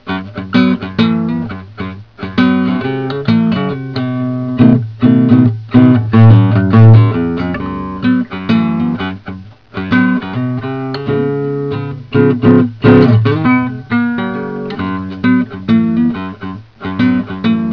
All that is left is to do now is hammer-on the major 3rd (note in red) which is a C# in "Amin". In the audio example, this riff can be heard with an occasional droning open "A" in between to keep the minor tonality in mind.
blues.wav